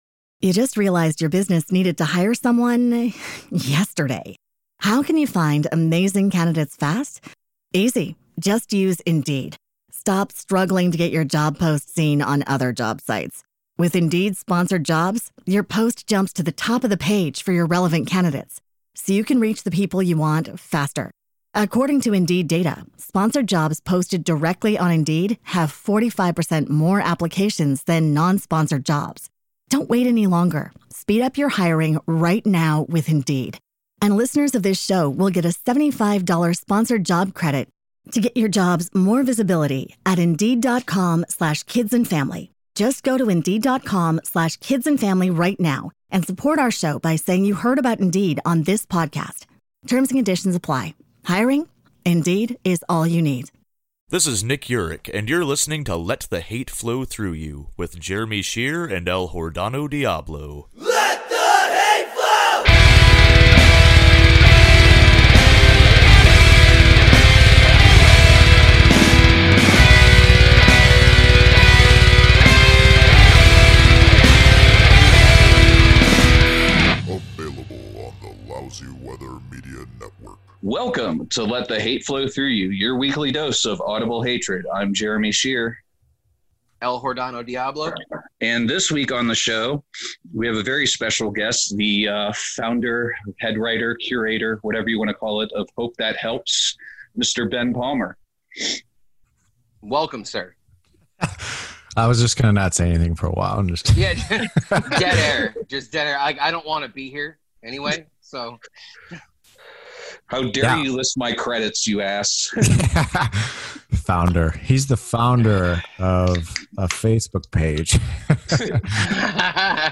Recorded with Zoom.